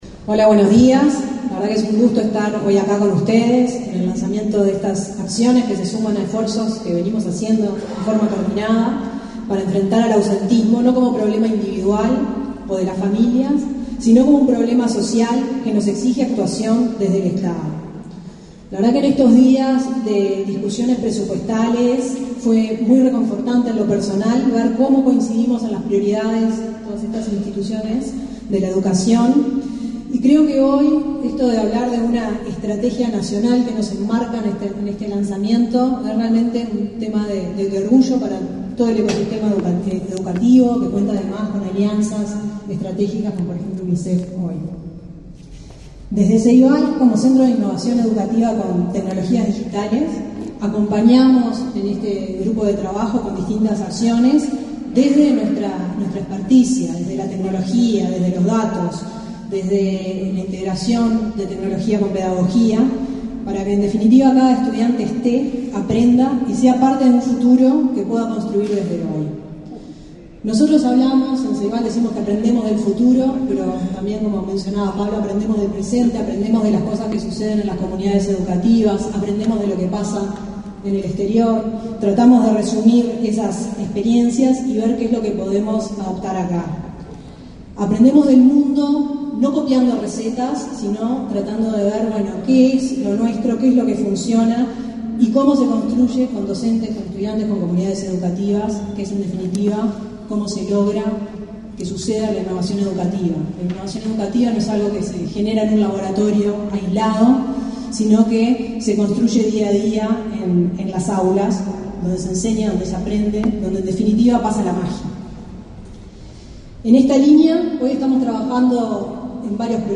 Palabras de la presidenta de Ceibal, Fiorella Haim